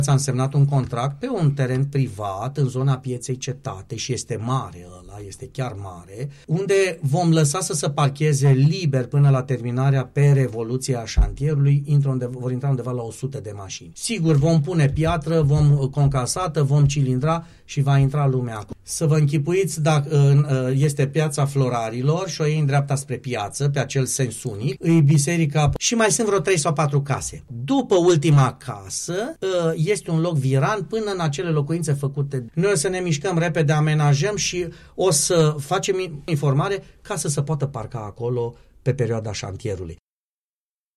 Primarul municipiului Alba Iulia, Gabriel Pleșa, a precizat, la Unirea FM, că această parcare provizorie va fi funcțională în perioada imediat următoare, după ce o vor amenaja.